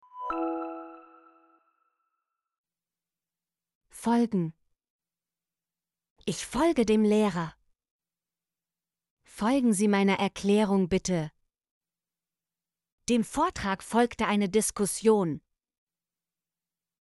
folgen - Example Sentences & Pronunciation, German Frequency List